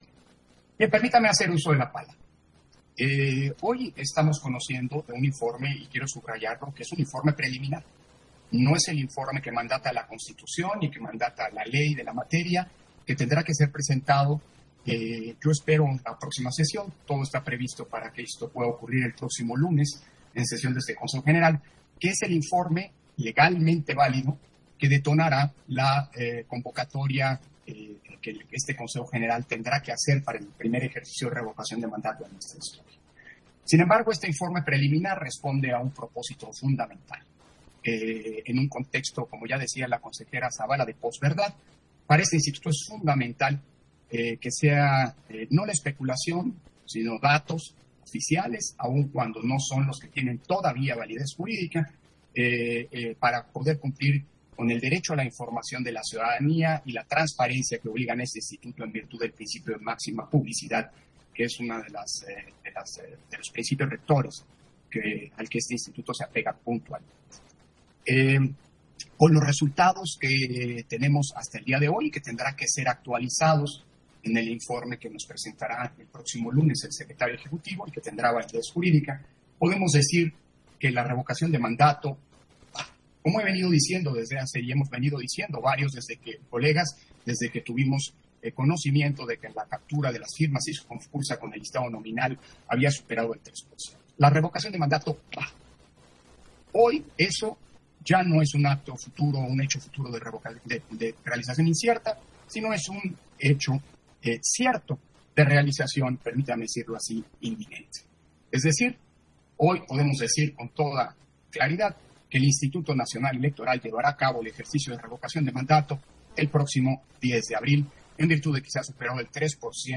260122_AUDIO_INTERVENCIÓN-CONSEJERO-PDTE.-CÓRDOVA-PUNTO-2-SESIÓN-EXT. - Central Electoral